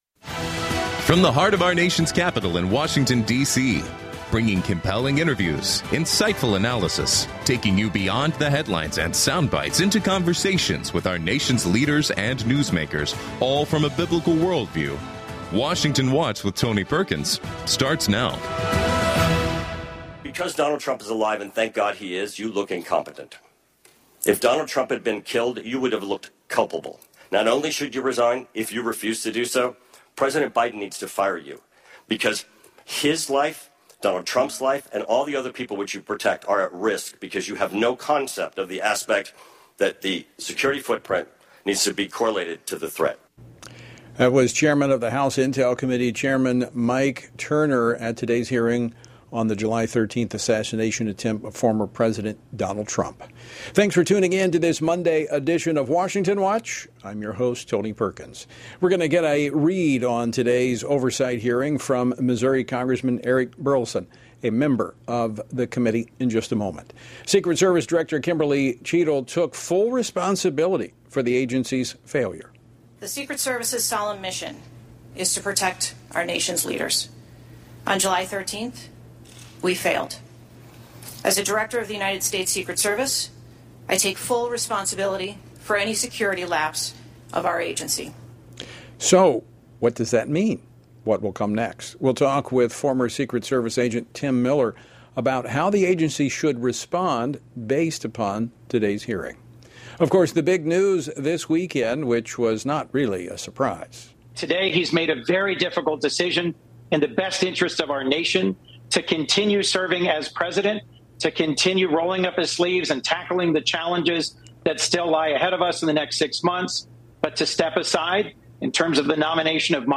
Washington Watch is a daily program hosted by Family Research Council President Tony Perkins that covers the issues of faith, family and freedom.